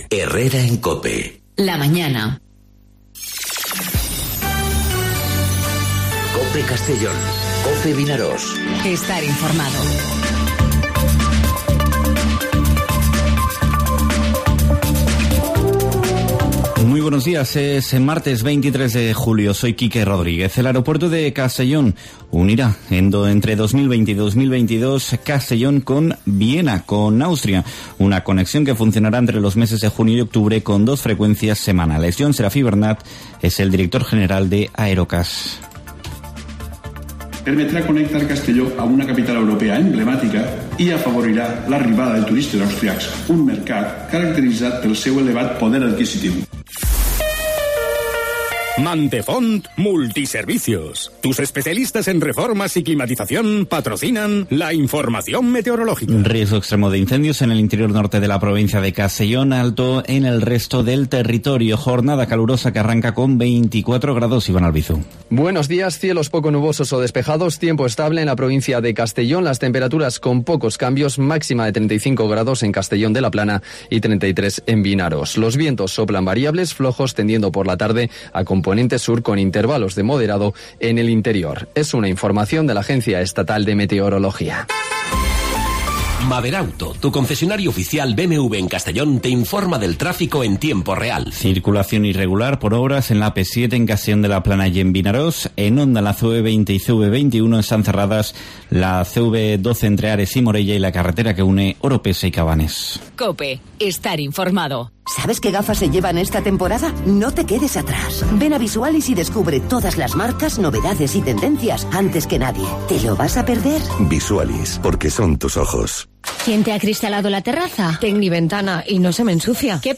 Informativo 'Herrera COPE' en Castellón (23/07/2019)